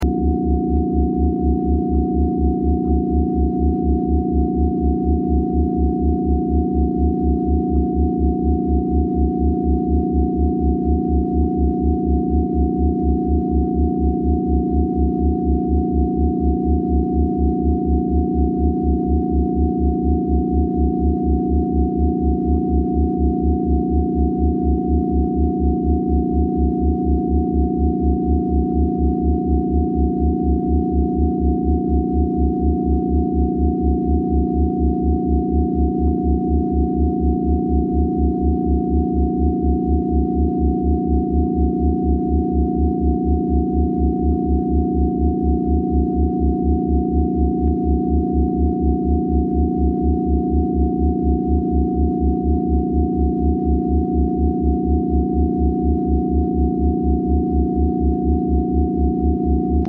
Liver Cleanse with Rife Frequencies